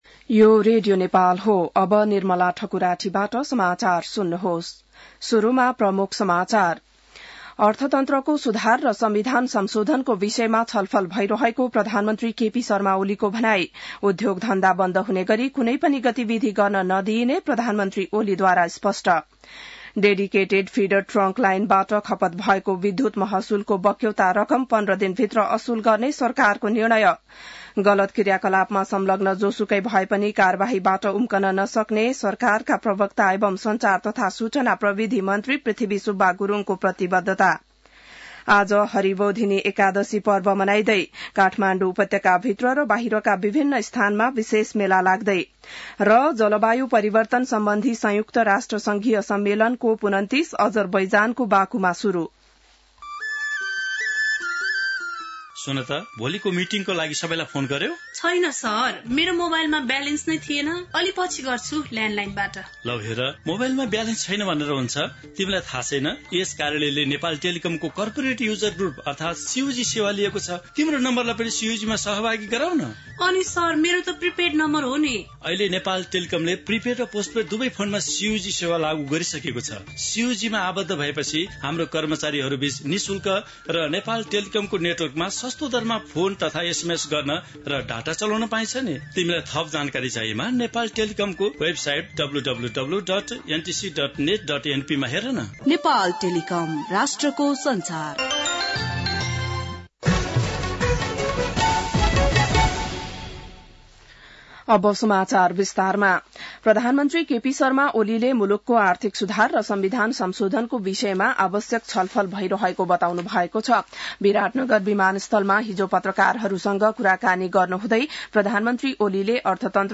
बिहान ७ बजेको नेपाली समाचार : २८ कार्तिक , २०८१